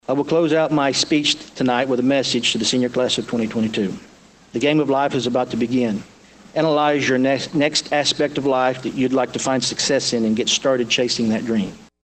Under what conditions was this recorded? A packed Holly C. Ward gymnasium on the Caney Valley Public Schools' campus sees loved ones look on as their graduates receive their diplomas.